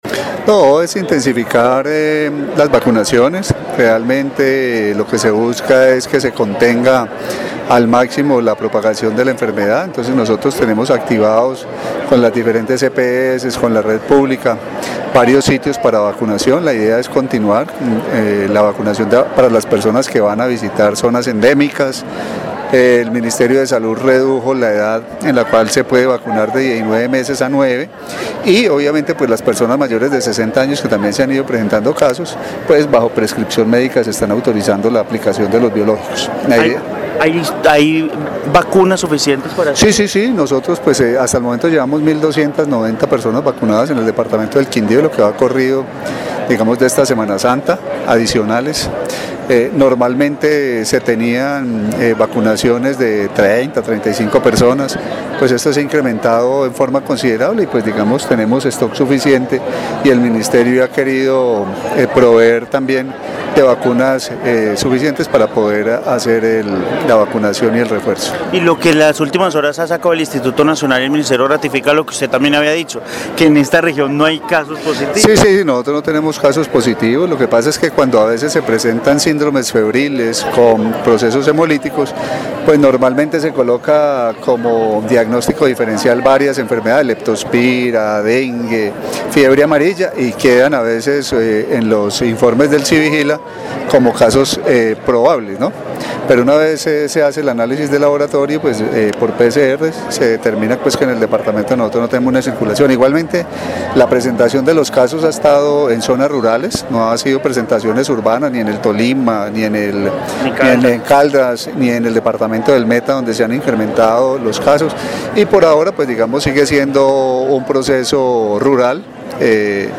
Carlos Alberto Gómez. secretario de salud del Quindío